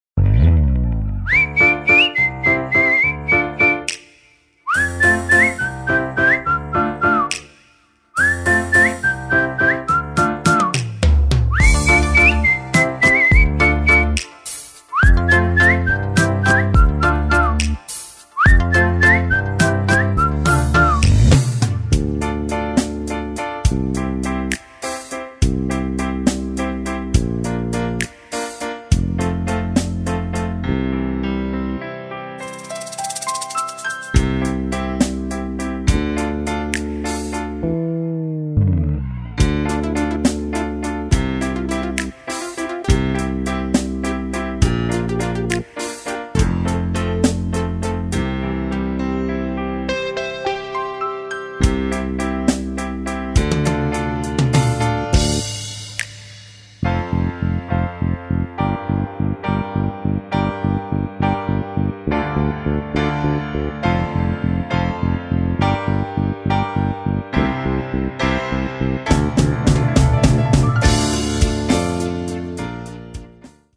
توضیحات: اهنگ با سوت معروف